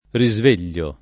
risveglio [ ri @ v % l’l’o ] s. m.; pl. ‑gli